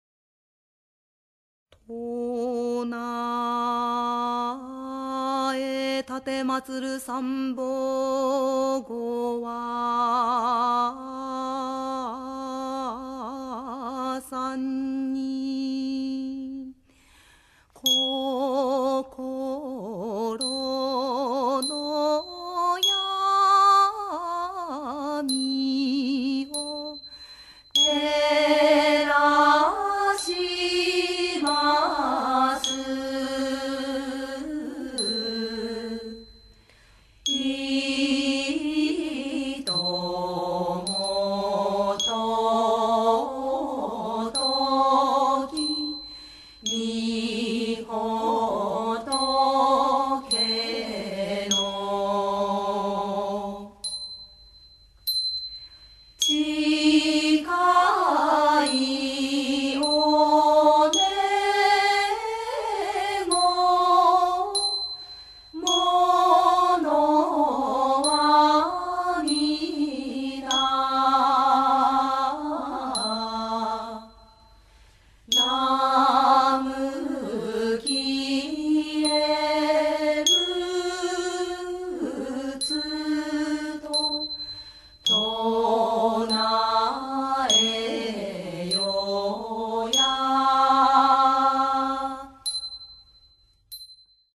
梅花流詠讃歌